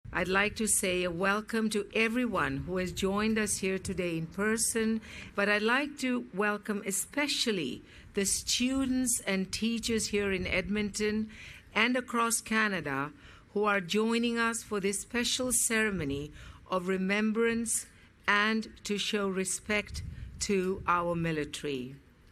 Lieutenant Governor of Alberta, the Honorable Salma Lakhani was on site and welcomed the students :